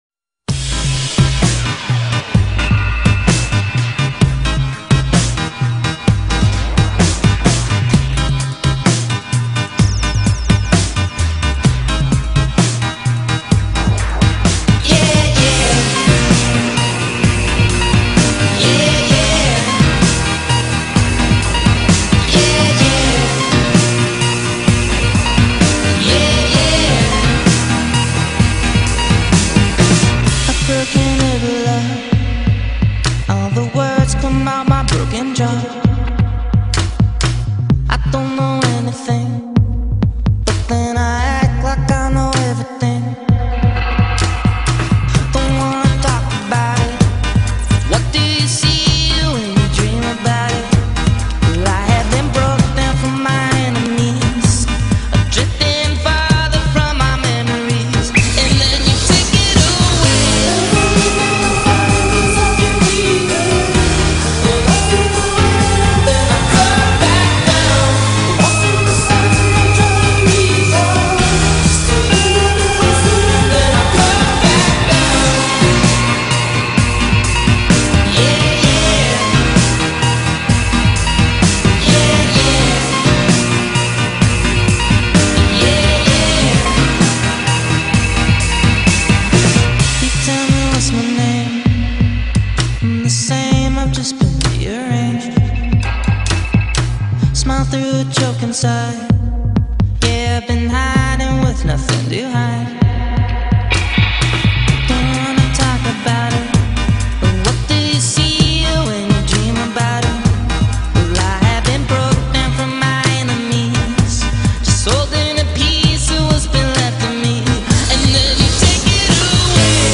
so we can finally hear it in studio form.